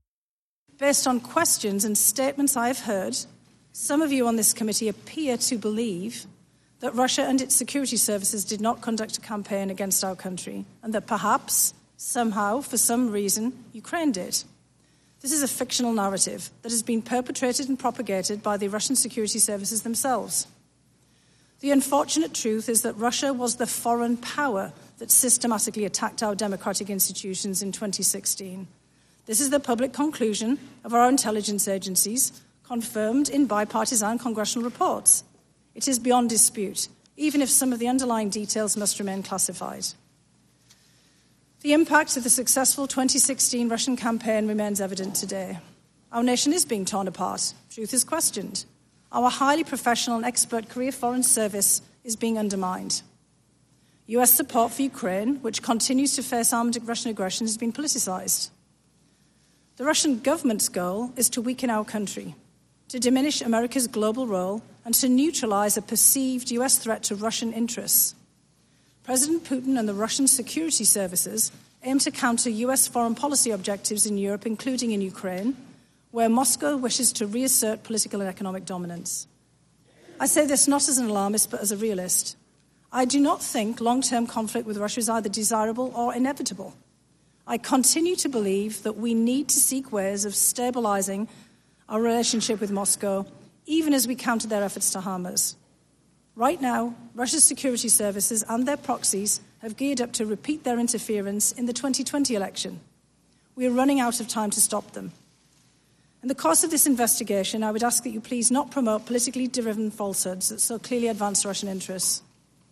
Dr. Fiona Hill, the former top Russia expert on the National Security Council, testifying before Congress on 21 Nov
Check out the audio clip below of Dr. Hill schooling the House Intelligence Committee…this gal rocks!!